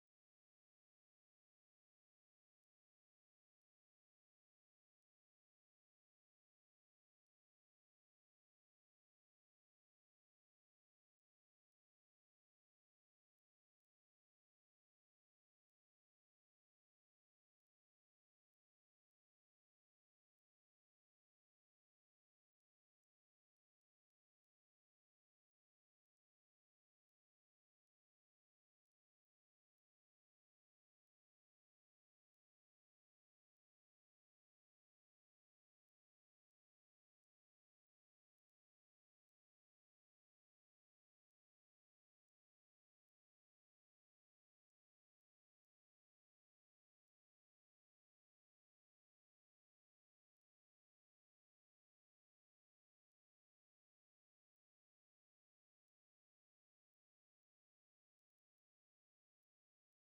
Legenda: O silêncio é de ouro.
silence.mp3